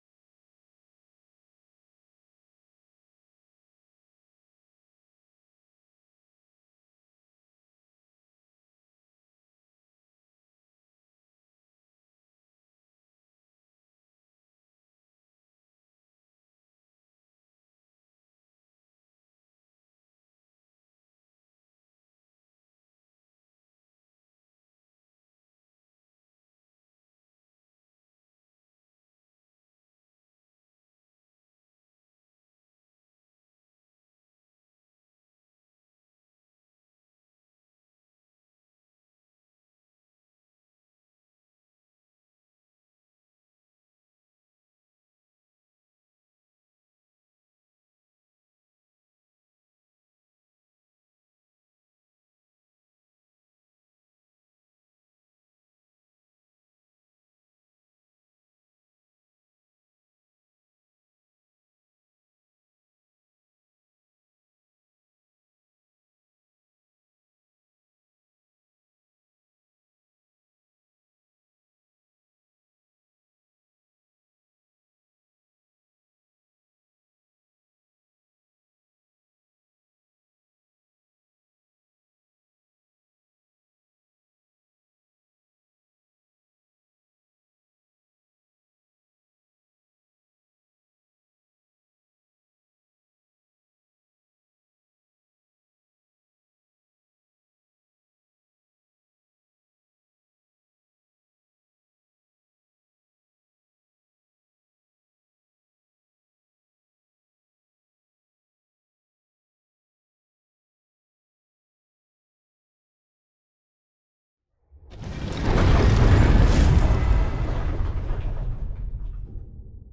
UI_Scene01_StoneDoor.ogg